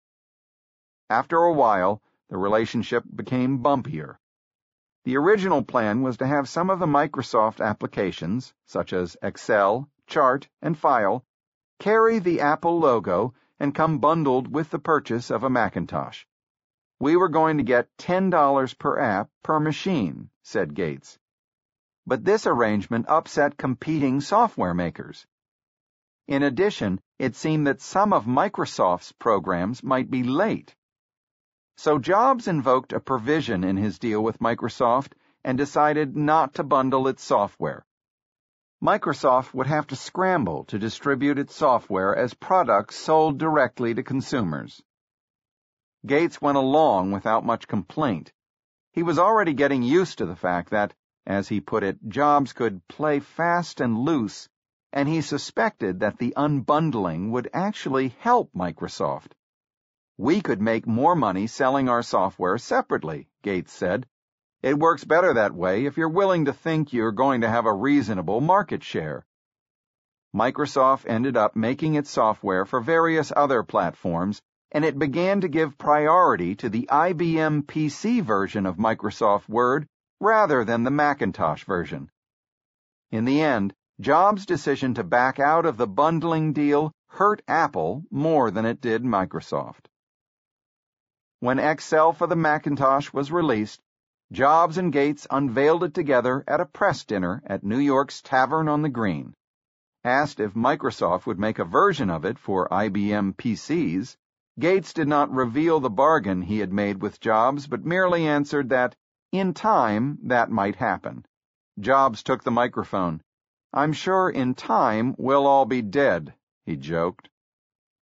在线英语听力室乔布斯传 第167期:盖茨与乔布斯(5)的听力文件下载,《乔布斯传》双语有声读物栏目，通过英语音频MP3和中英双语字幕，来帮助英语学习者提高英语听说能力。
本栏目纯正的英语发音，以及完整的传记内容，详细描述了乔布斯的一生，是学习英语的必备材料。